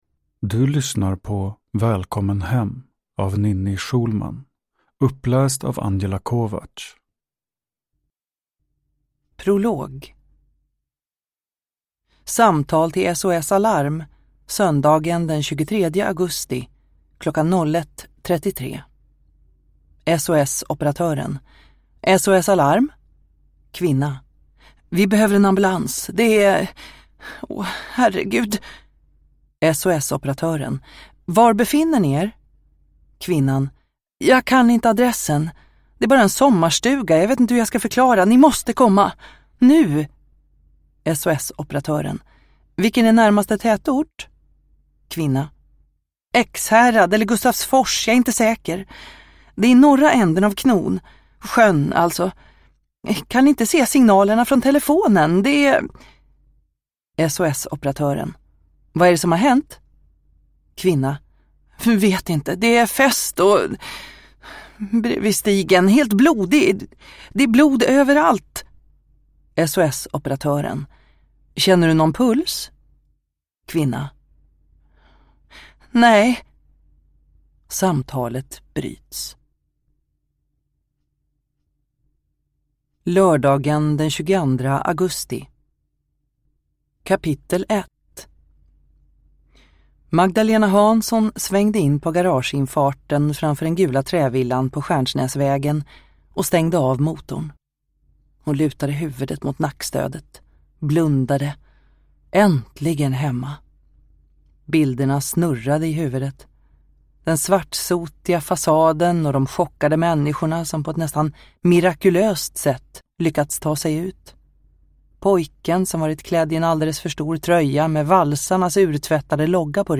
Välkommen hem – Ljudbok